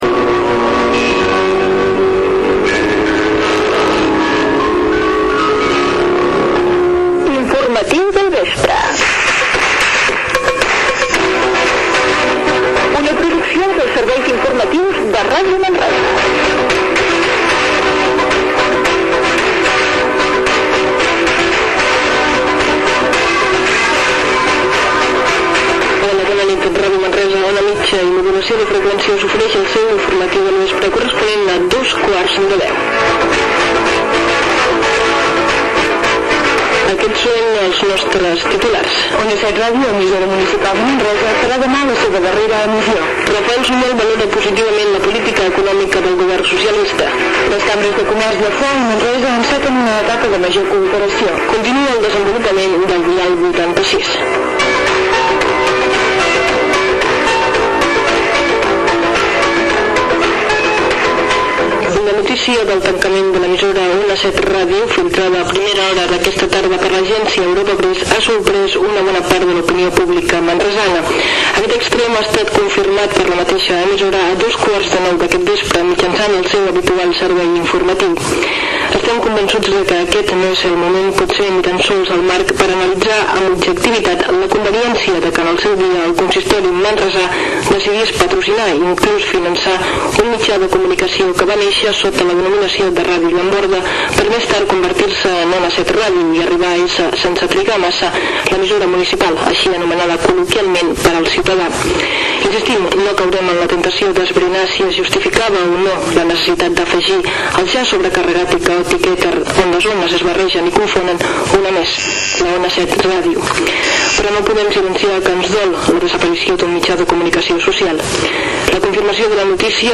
Careta del programa, titulars, tancament d'Ona 7 Ràdio, indicatiu del programa.
Informatiu